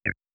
Frog.wav